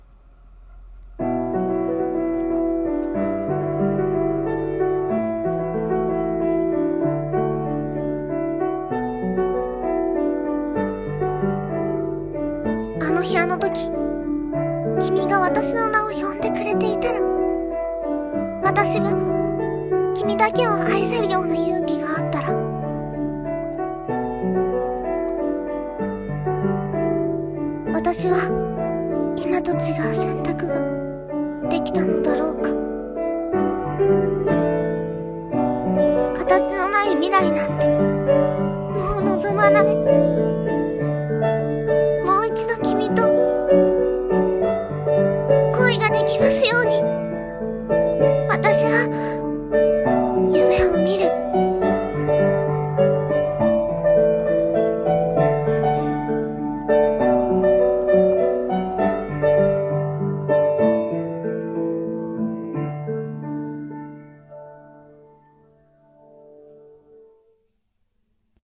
【声劇 朗読】あの日の選択